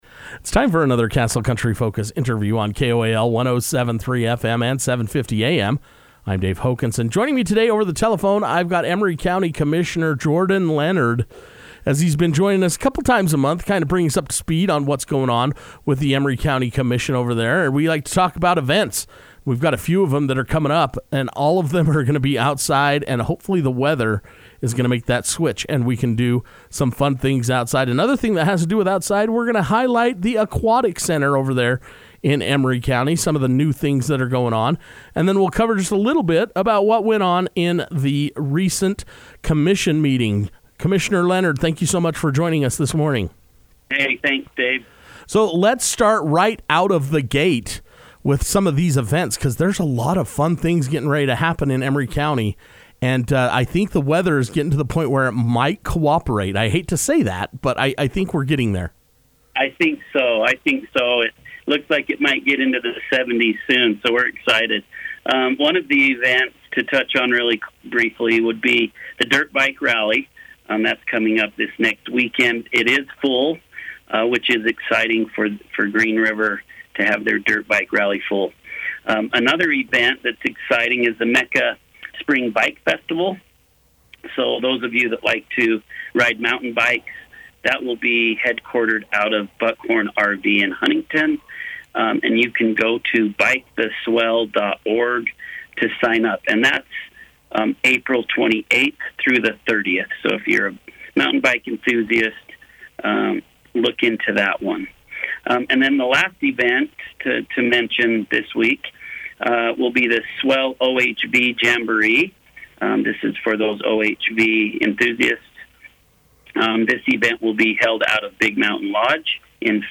It’s time to sit down with Emery County Commissioner Jordan Leonard to find out about some upcoming events and highlight the aquatic center on a job well done. Castle Country Radio will now have an opportunity every other week to speak with the commissioner to keep citizens informed on the latest news and events.